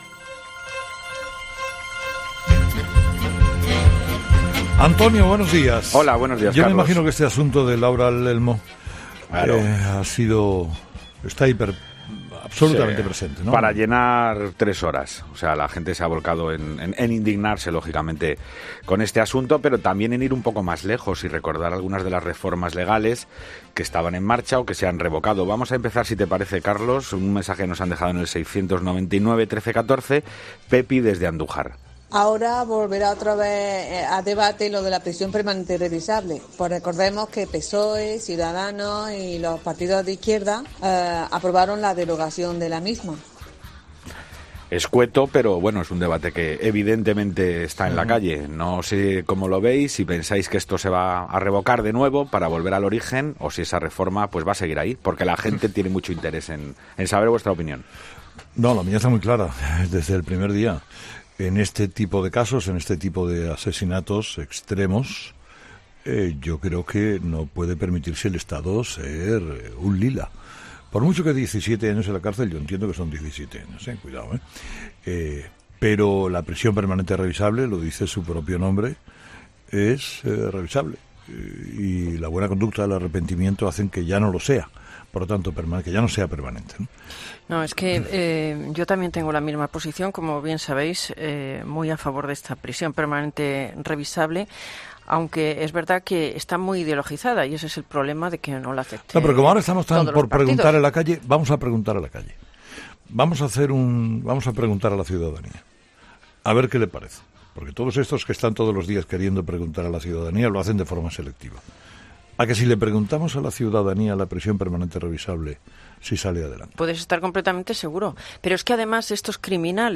Ha sido el contenido principal de la sección que Carlos Herrera ofrece a sus seguidores para que pongan sobre la mesa sus reflexiones. Tampoco pueden perderse el testimonio de una señora que nos ha contado cómo se siente sabiendo que varias casas al lado vive un hombre que acaba de salir de la cárcel .